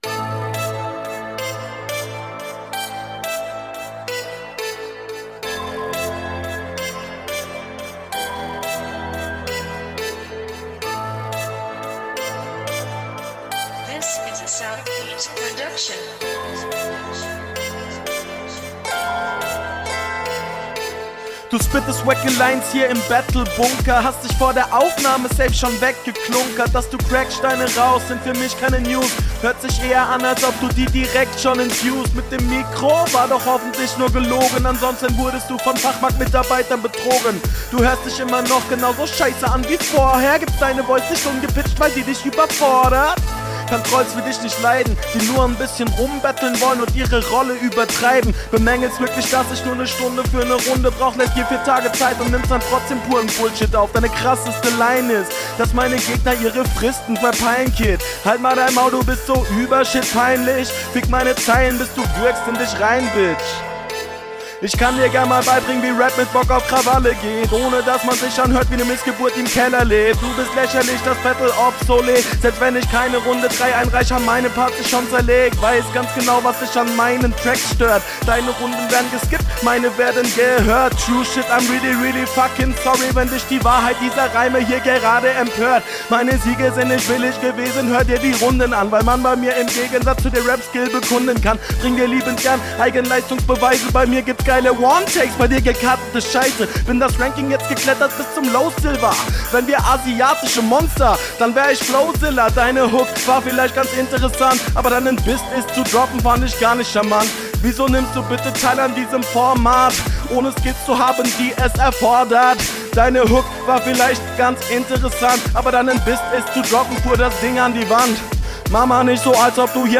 Flow ok, Mix auch, Punches sind mid, Reime gehen klar
Flow ein bisschen stockend aber okay.